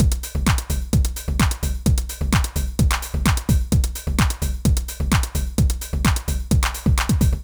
INT Beat - Mix 3.wav